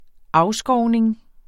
Udtale [ ˈɑwˌsgɒwneŋ ]